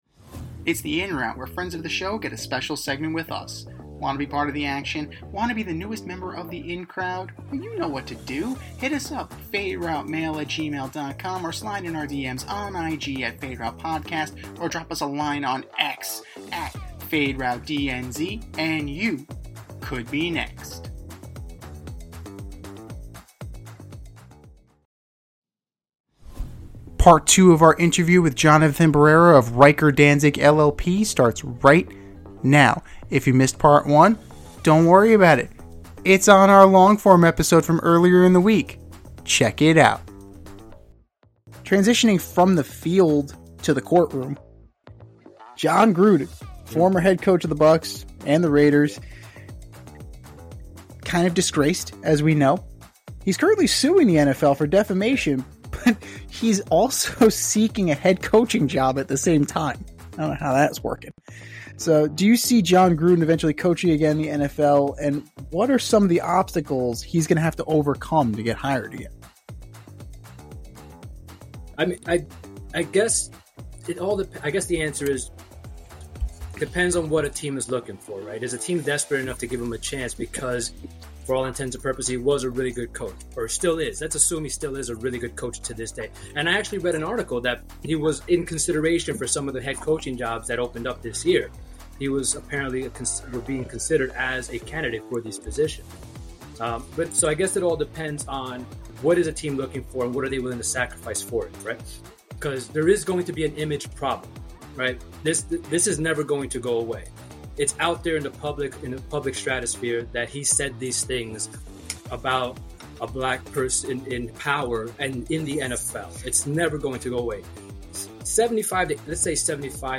In part 2 of our interview